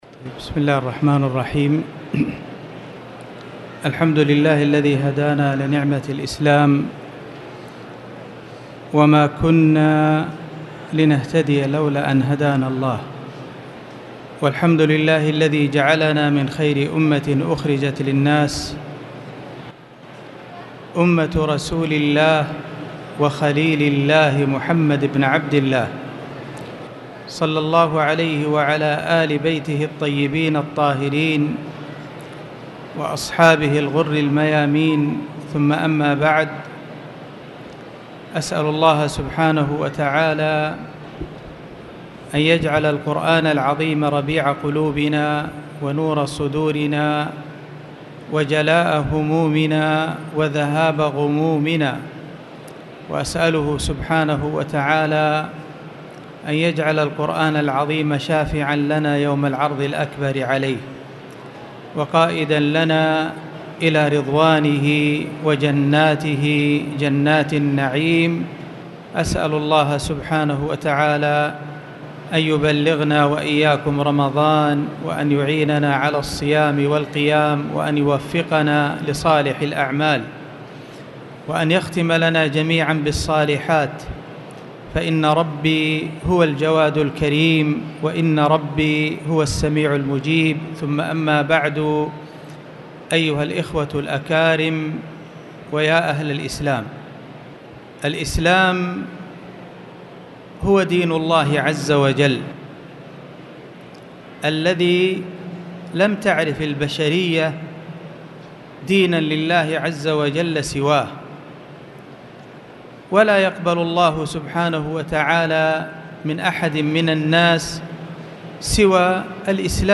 تاريخ النشر ١٩ شعبان ١٤٣٨ هـ المكان: المسجد الحرام الشيخ